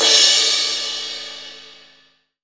• Crash Cymbal Sound F Key 03.wav
Royality free crash single hit tuned to the F note. Loudest frequency: 4748Hz
crash-cymbal-sound-f-key-03-FN6.wav